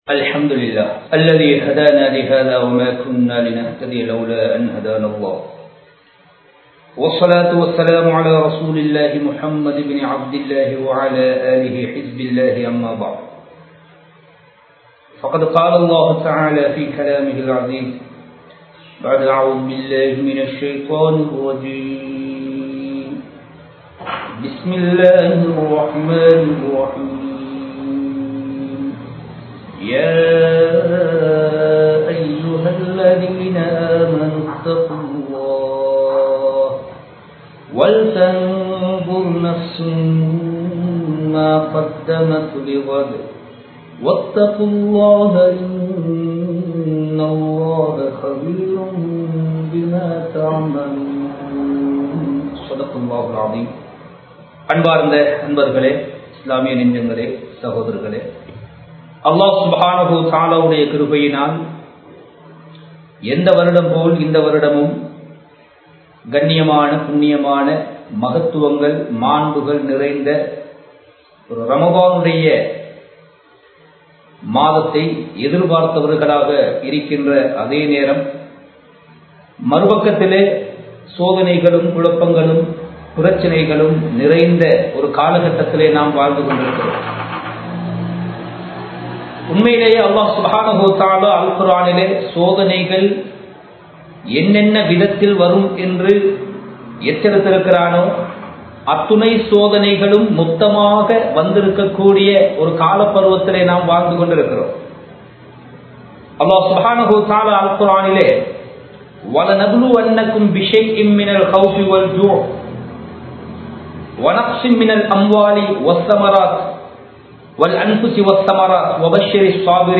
சோதனைகள் புகட்டும் பாடம் | Audio Bayans | All Ceylon Muslim Youth Community | Addalaichenai
Live Stream